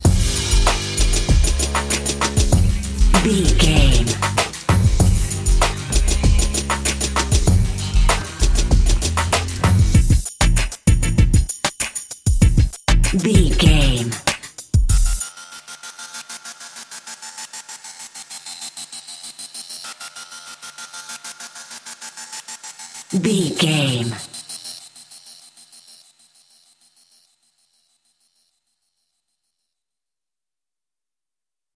Aeolian/Minor
DOES THIS CLIP CONTAINS LYRICS OR HUMAN VOICE?
WHAT’S THE TEMPO OF THE CLIP?
synthesiser
drum machine